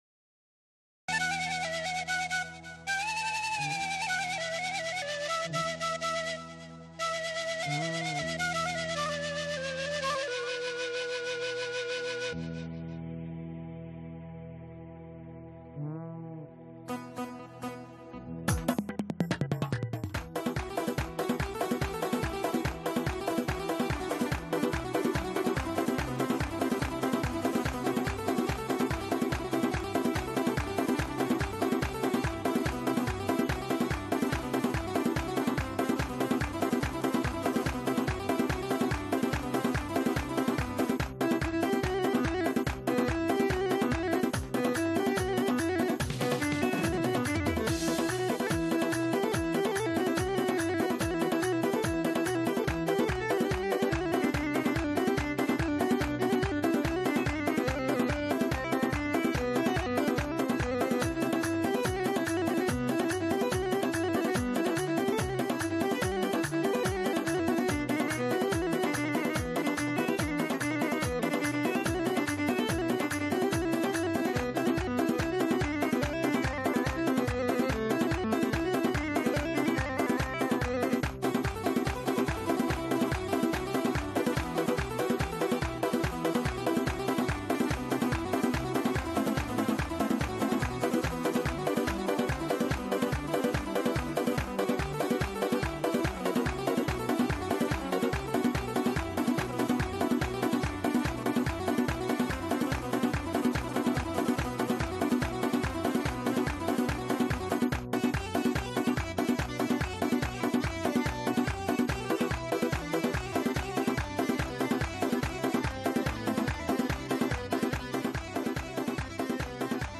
گوێ ڕادەگرین به گۆرانیێکی شادی کوردی